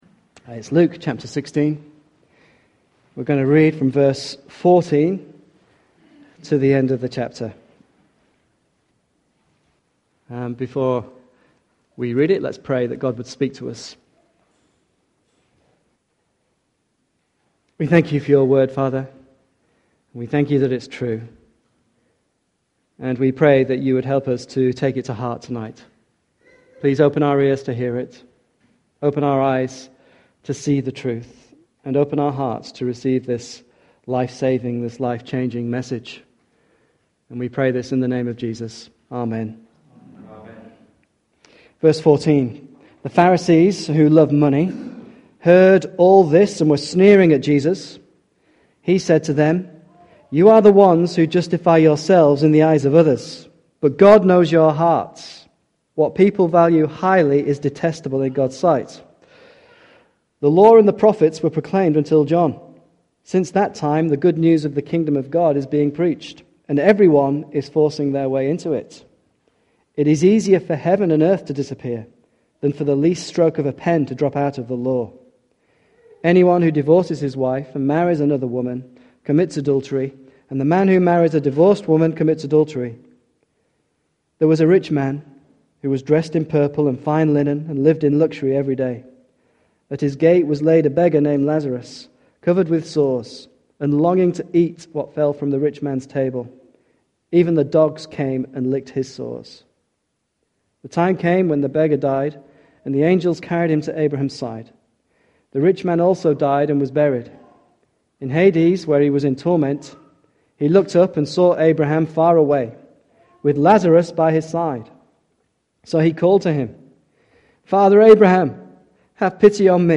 Service Type: Evening Service